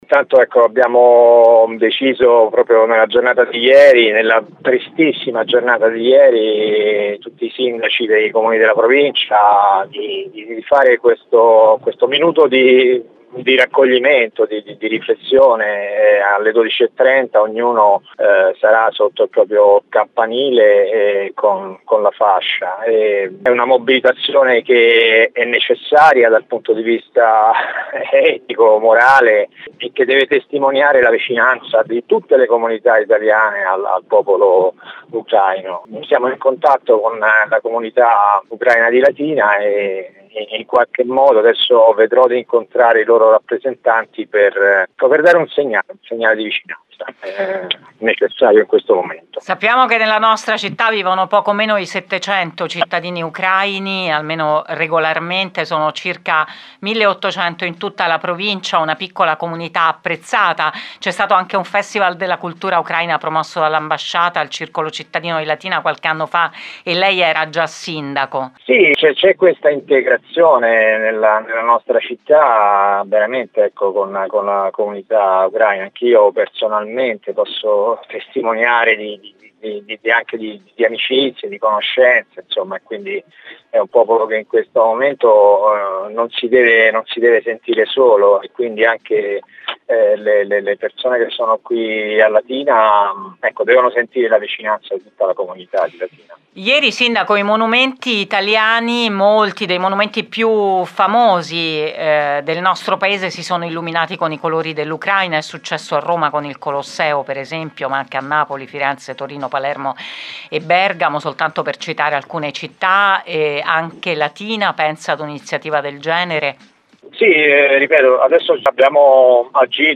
Ne abbiamo parlato con lui questa mattina su Radio Immagine.